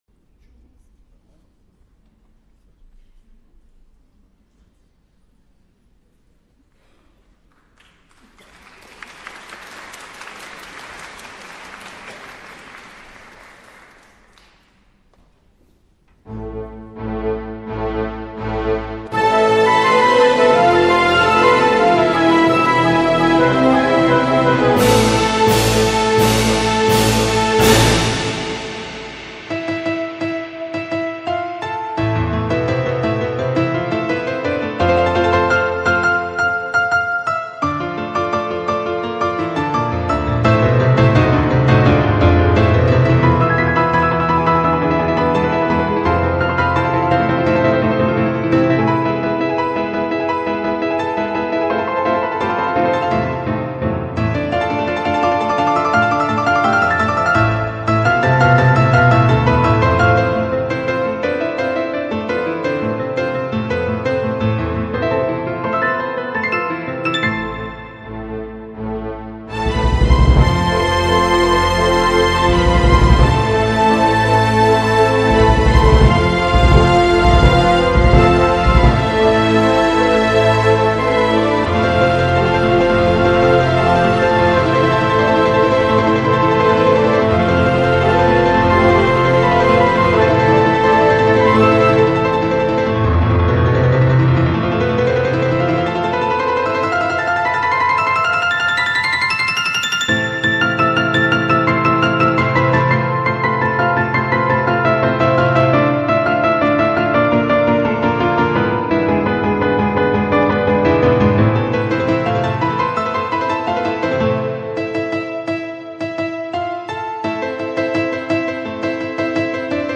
PIANO CONCERT (parodi)